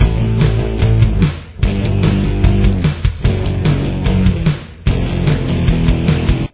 Main Riff